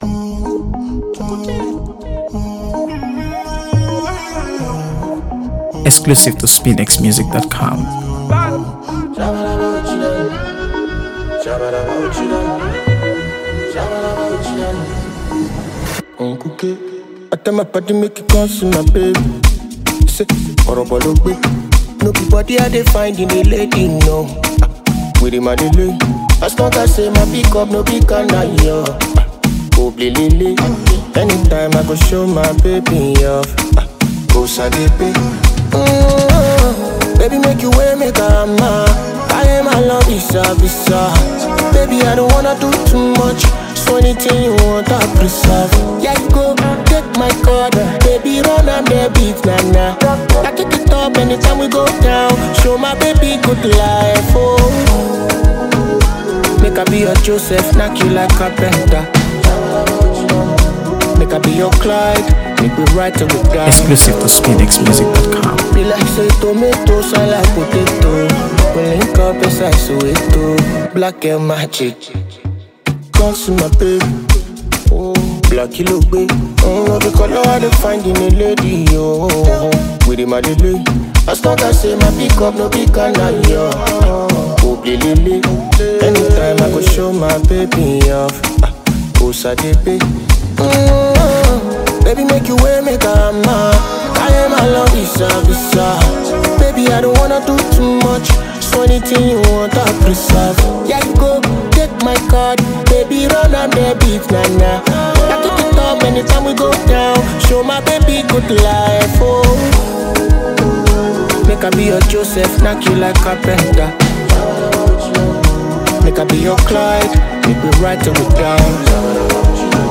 AfroBeats | AfroBeats songs
With his signature smooth vocals and infectious rhythms